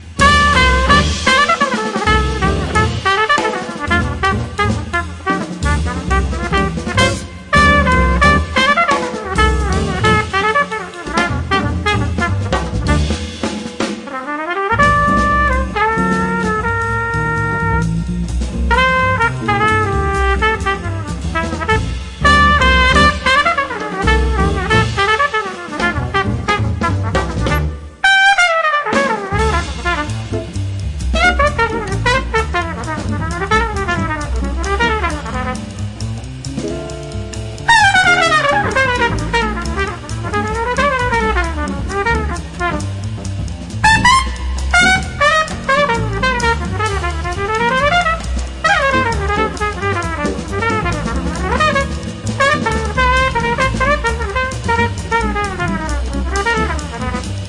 The Best In British Jazz
Recorded Curtis Schwartz Studios, December 15th 2003